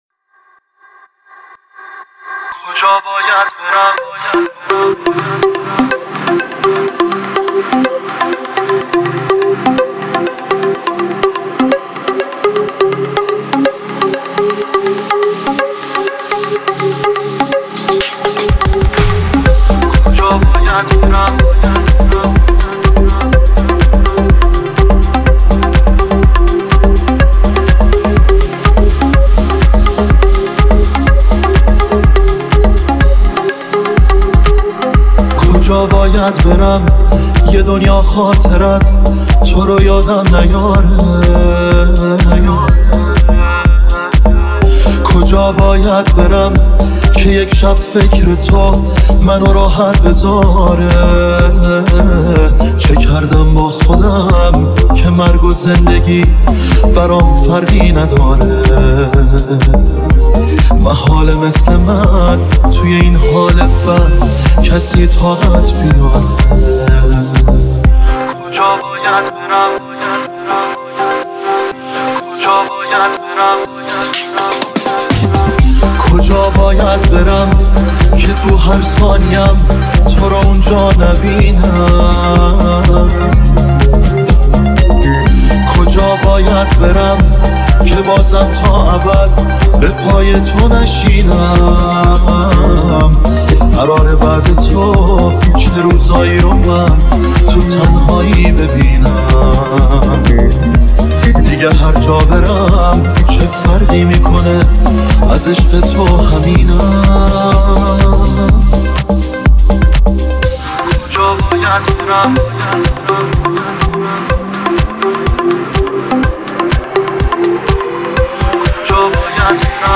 ریمیکس دوم